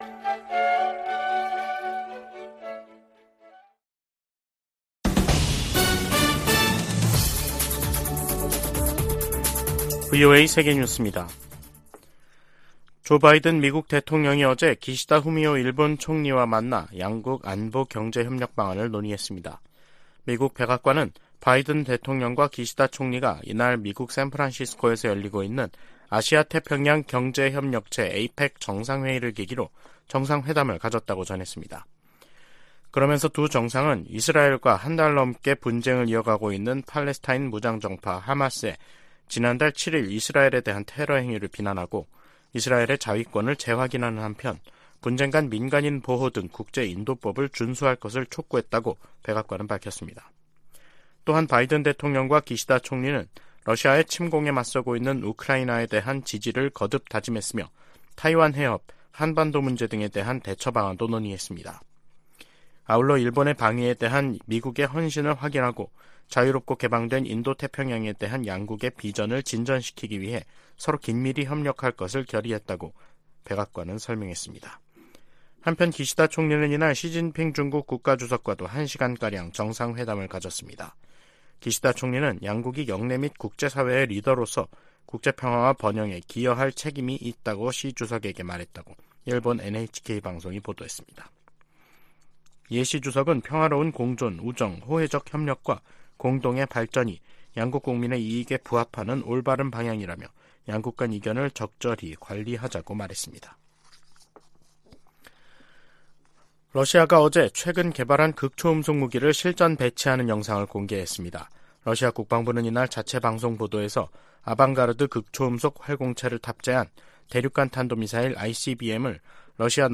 VOA 한국어 간판 뉴스 프로그램 '뉴스 투데이', 2023년 11월 17일 2부 방송입니다. 조 바이든 미국 대통령과 기시다 후미오 일본 총리가 타이완해협, 한반도, 동중국해 등에서의 평화와 안정이 중요하다는 점을 재확인했습니다. 미국은 동맹국의 핵무기 추구를 단호히 반대해야 한다고 국무부의 안보 관련 자문위원회가 주장했습니다. 미중 정상이 양국 간 갈등을 완화하는 데 동의함으로써 한반도 안보 상황에도 긍정적으로 작용할 것이라는 관측이 나오고 있습니다.